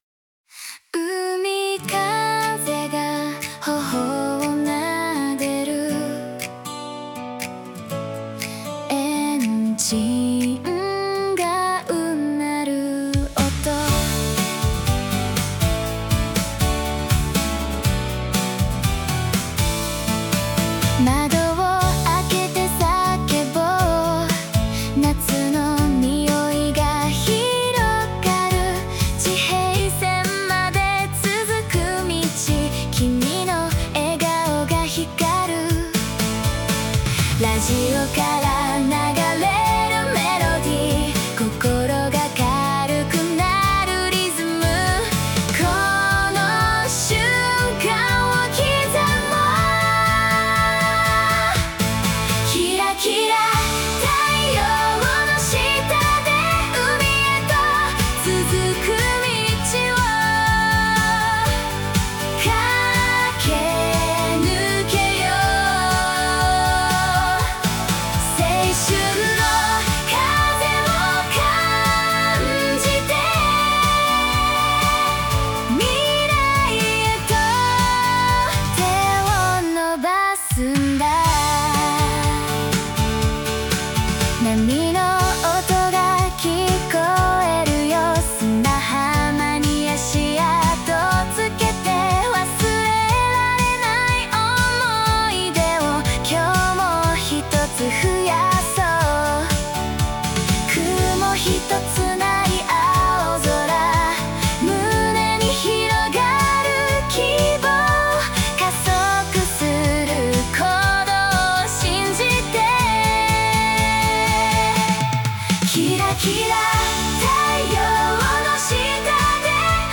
爽やかな夏の青春ポップソング。
前向きでキラキラした感じ。
• 女性ボーカル、ポップ、明るい雰囲気、軽快なアコースティックギターのストローク、ミッドテンポ（120 BPM）、軽いリバーブがかかったクリアなボーカル、重なり合うハーモニー、夏のビーチのような雰囲気
AIが自動で生成した曲